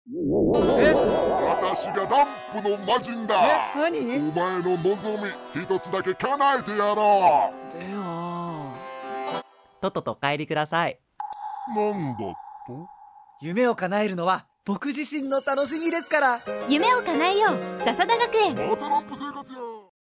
第16回SBSラジオCMコンテスト